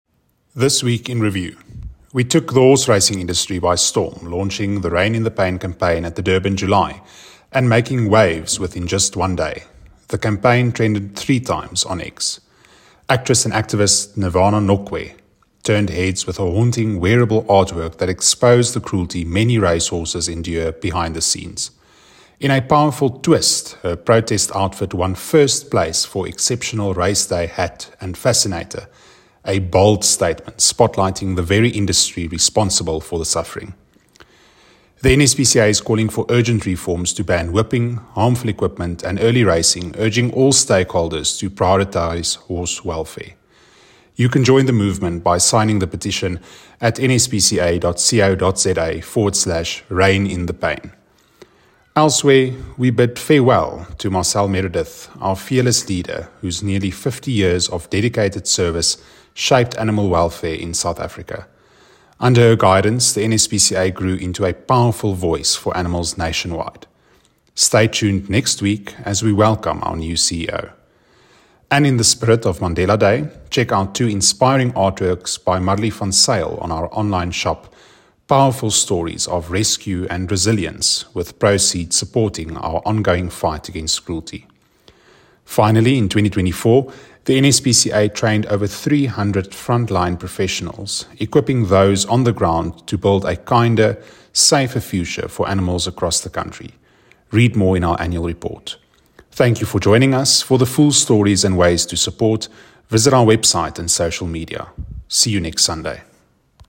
WiR-Voice-Over-mp3.mp3